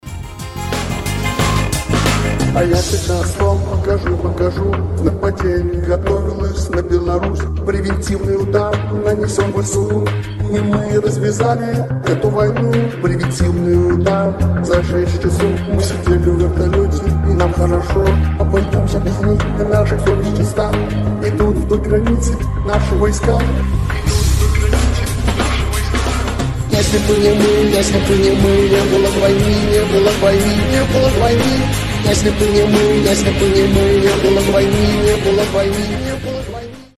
• Качество: 320, Stereo
Mashup
ремиксы